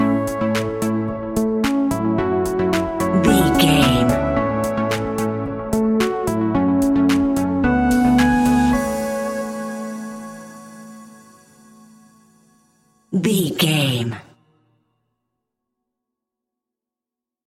Ionian/Major
groovy
uplifting
driving
energetic
repetitive
synthesiser
drums
electric piano
electronic
techno
synth drums
synth leads
synth bass